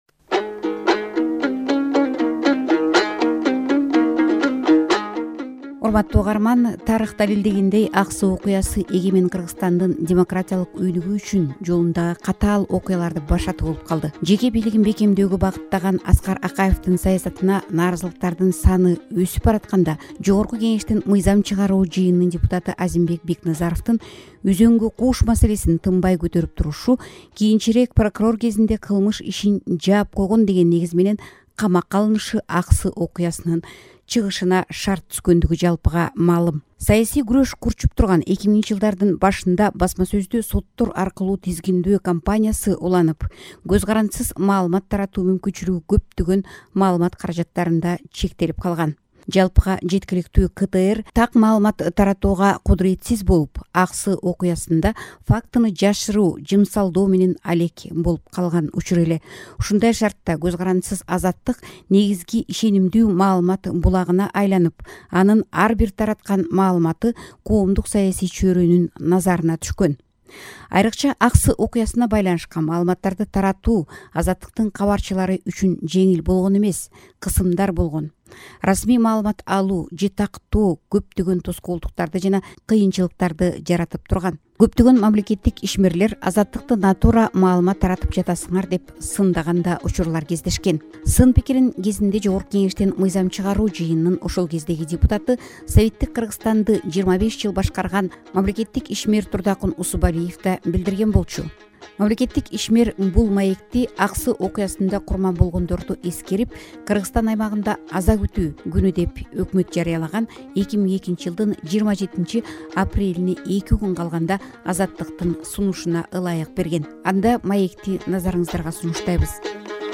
Интервью 11 жыл мурда, Аксы окуясынан бир ай өткөндөн кийин жаздырылган.